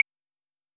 S 78_Clave.wav